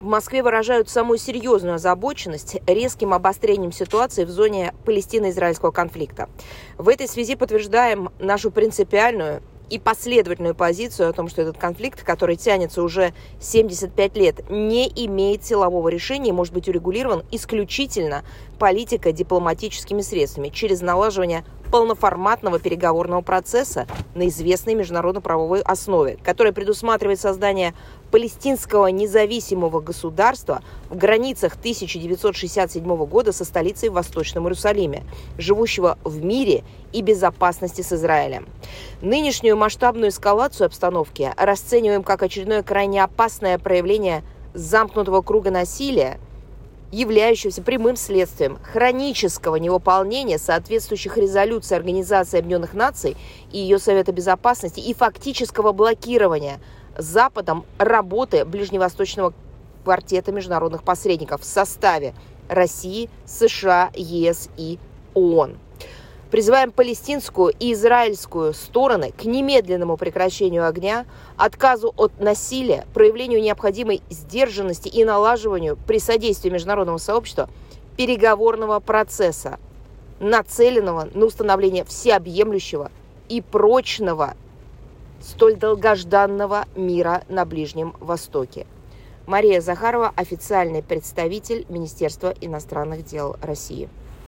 Комментарий официального представителя МИД России М.В.Захаровой в связи c резким обострением ситуации в зоне палестино-израильского конфликта
Комментарий М.В.Захаровой.mp3